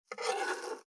564魚切る,肉切りナイフ,まな板の上,包丁,
効果音